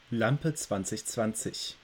My name's pronounciation